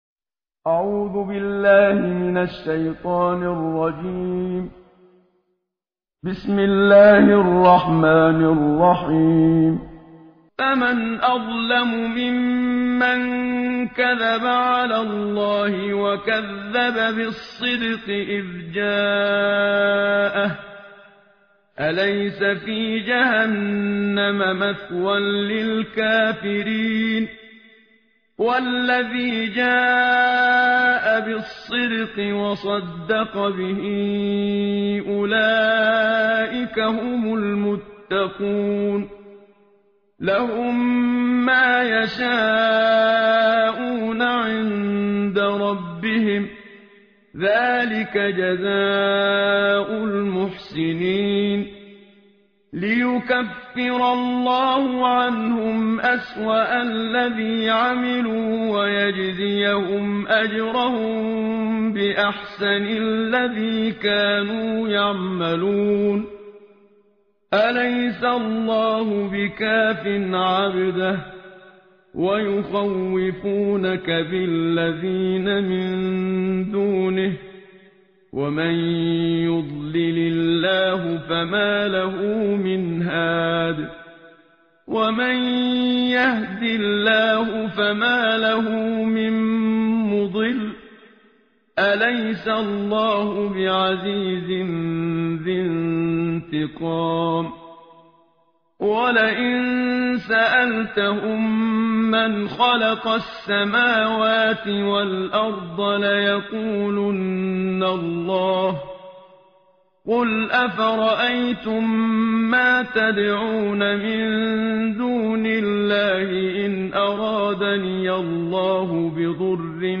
ترتیل جزء بیست و چهارم قرآن با صدای استاد منشاوی
تهران- الکوثر: در بیست و چهارمین روز ماه مبارک رمضان، تلاوت جزء بیست و چهارم قرآن کریم را با صدای قاری مشهور مصری استاد محمد صدیق منشاوی، با هم می شنویم.